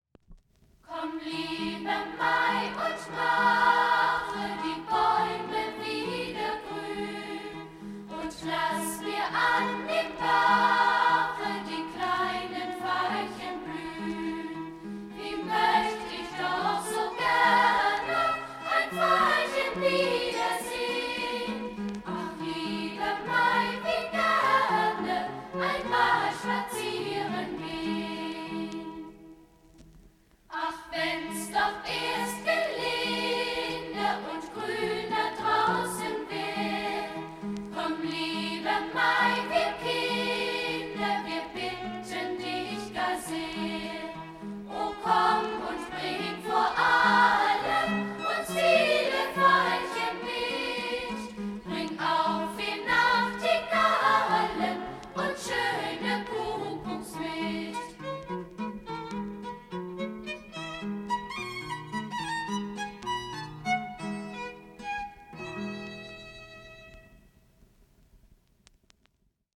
bekanntes deutschsprachiges Volks- und Kinderlied